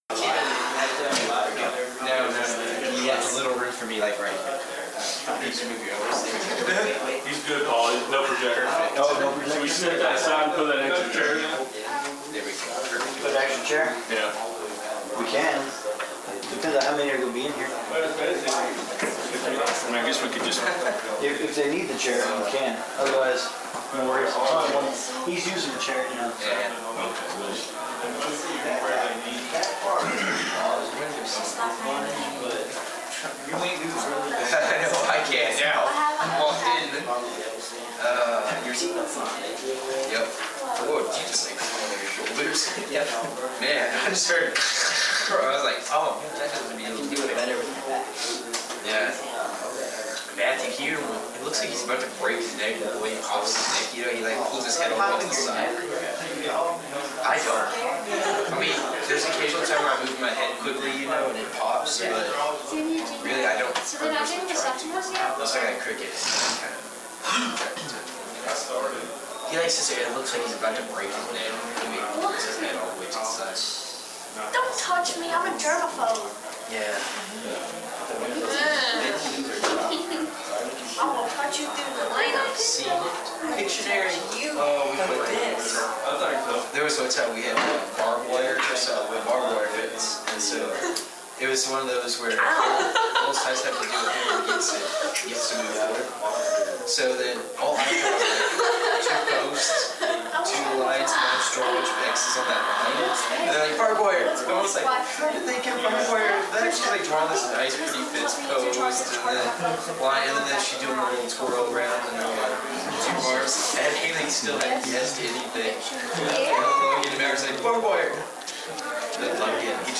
Youth Sessions
lecture